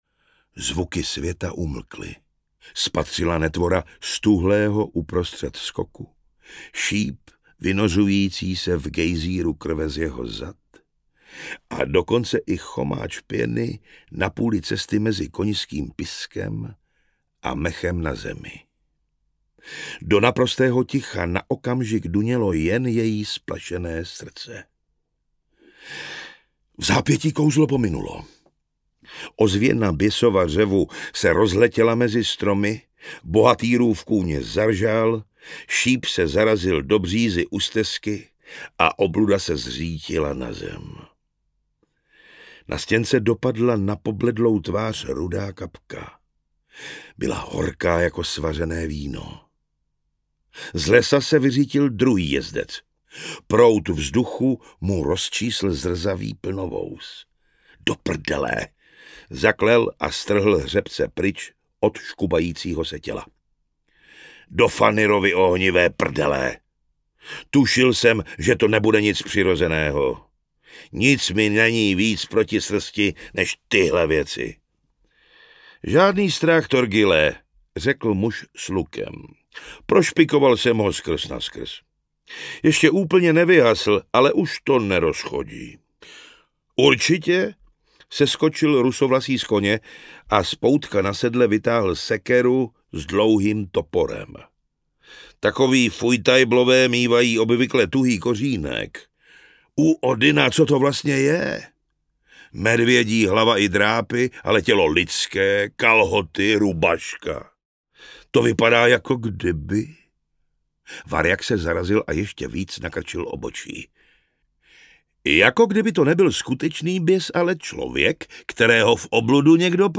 Černé srdce audiokniha
Ukázka z knihy